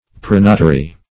Search Result for " pronotary" : The Collaborative International Dictionary of English v.0.48: Pronotary \Pro*no"ta*ry\, n. See Prothonotary .